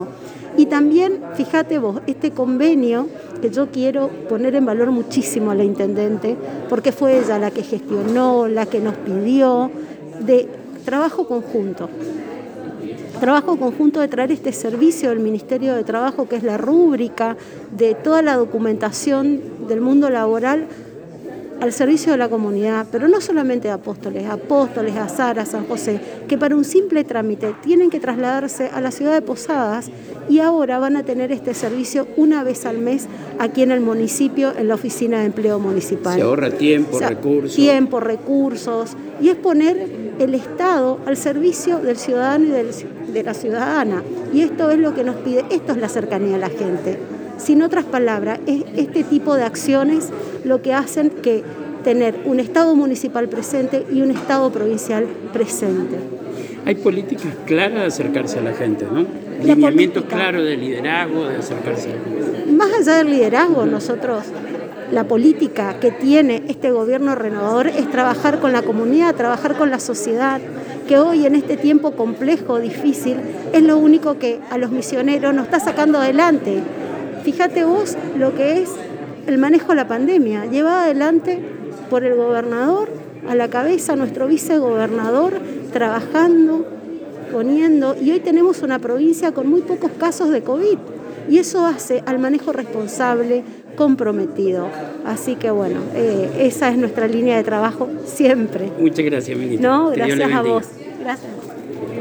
Estas afirmaciones las efectuó la Ministra de Trabajo de Misiones en entrevista con el programa «Lo Mejor de la Ciudad» y la ANG en su jornada de trabajo en la Ciudad de Apóstoles en momentos que se se llevó a cabo la firma del convenio de coparticipación y asistencia para rúbrica de documentación laboral por parte de la Intendente María Eugenia Safrán y la Ministra de Trabajo y Empleo de Misiones Silvana Giménez con el objetivo de facilitar los procedimientos necesarios.